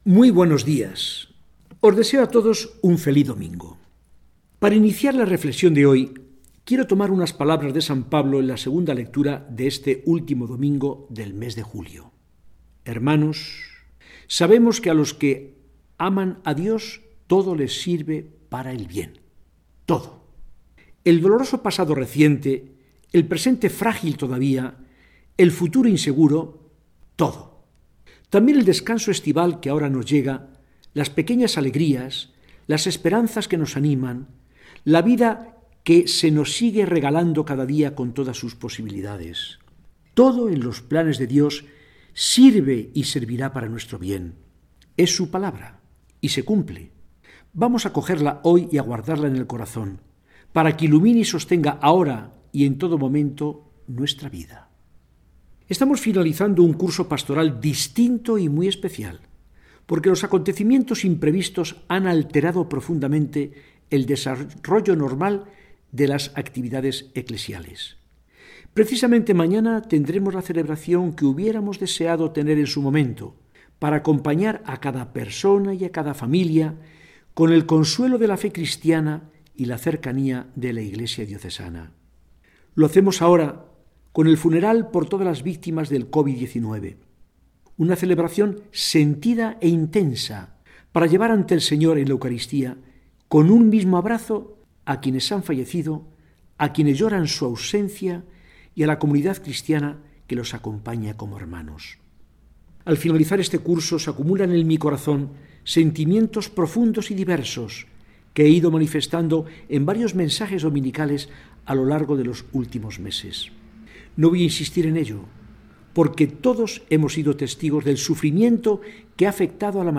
Mensaje del arzobispo de Burgos, don Fidel Herráez Vegas, para el domingo 26 de julio de 2020.